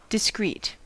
Ääntäminen
IPA : /dɪˈskɹiːt/